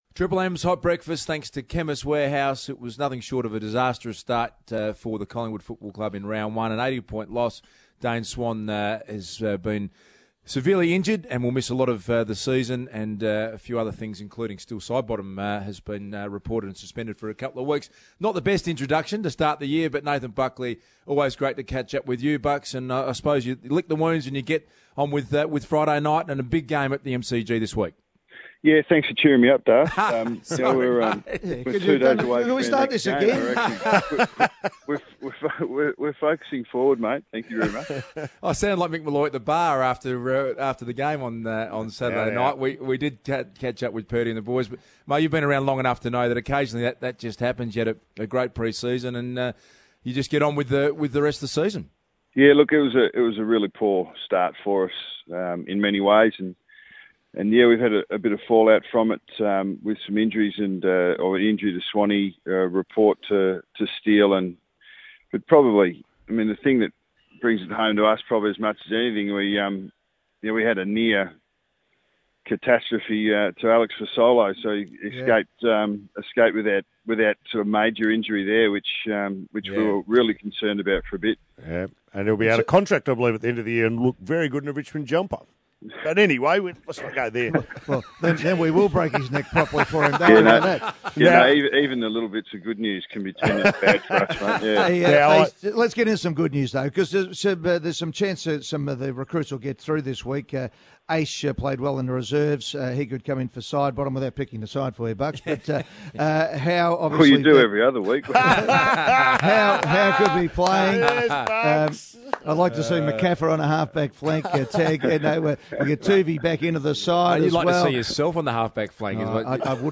Listen to coach Nathan Buckley join Eddie McGuire, Luke Darcy and Mick Molloy on Triple M's Hot Breakfast.